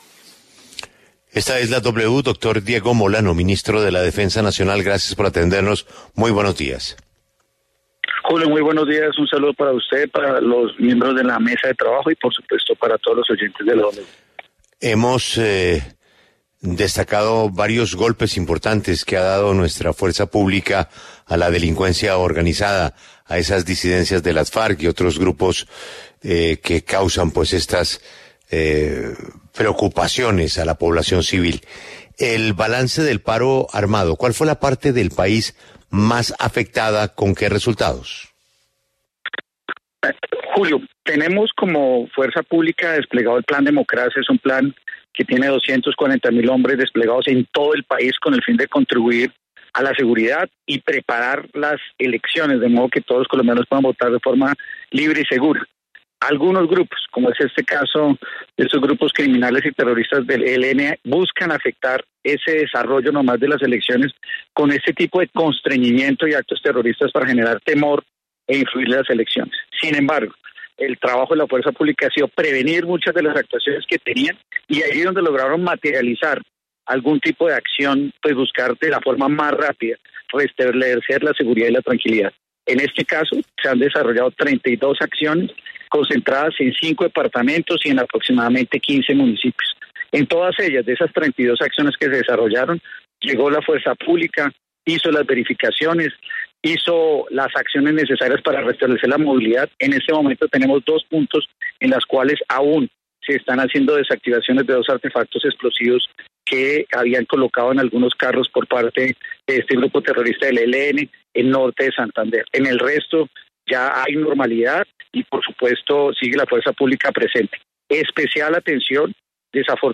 En diálogo con La W, el ministro de Defensa, Diego Molano, señaló de “cobardes” a los subversivos que atentan contra la Policía y luego se esconden en Venezuela como ha pasado en Arauca y Norte de Santander.